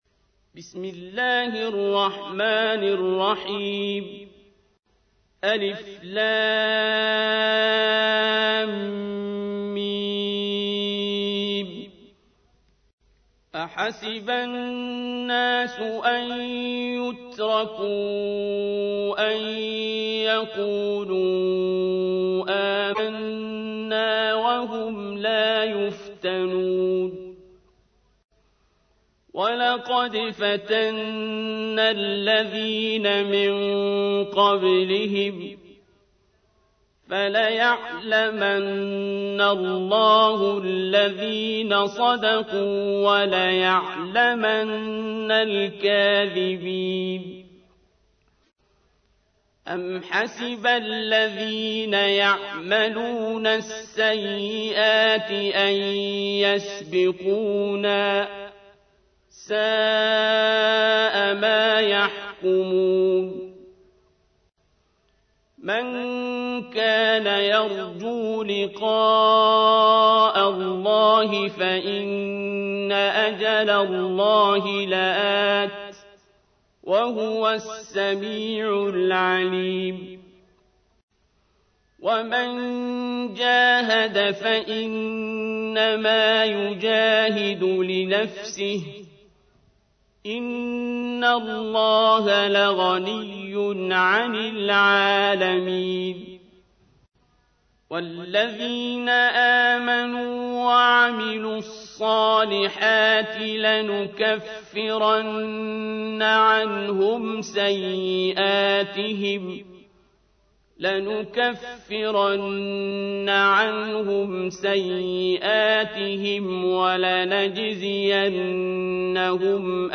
تحميل : 29. سورة العنكبوت / القارئ عبد الباسط عبد الصمد / القرآن الكريم / موقع يا حسين